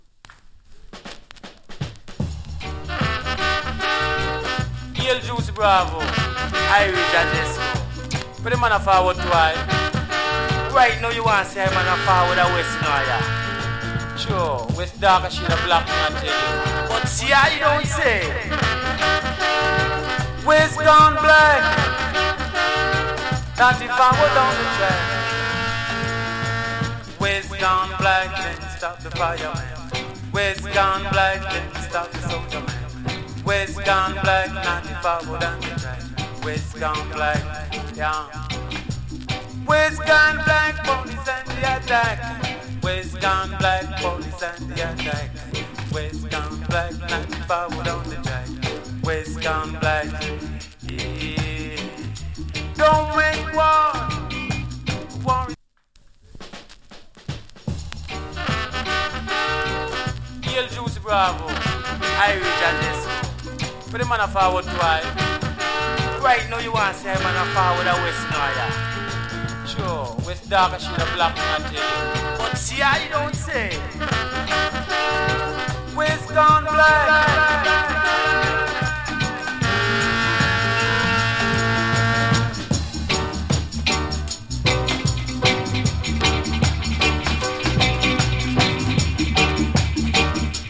プレスによるチリ、ジリノイズ少々有り。
DEE-JAY CUT !!
＋ FINE DUB.